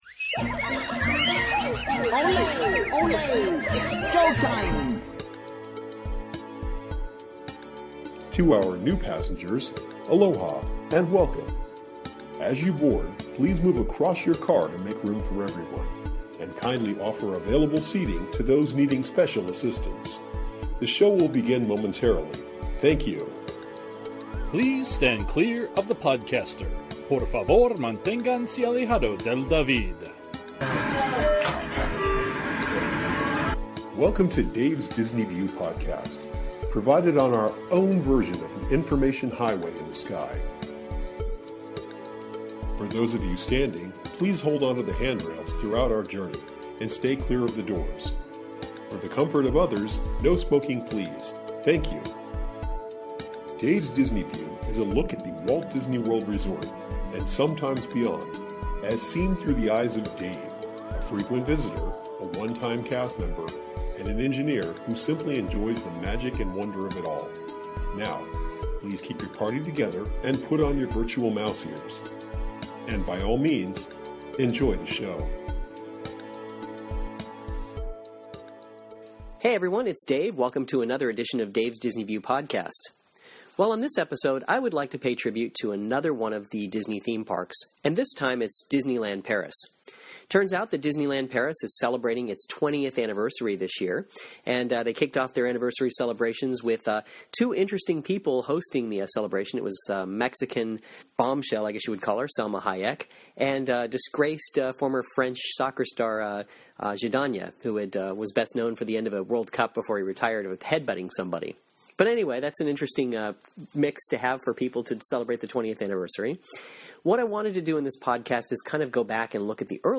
In the early days of EuroDisney, the Phantom Manor was really something. It featured Vincent Price as your ghost host, and really had a spooky feel to it. This podcast contains that audio from the attraction, as well as a few other thoughts about EuroDisney.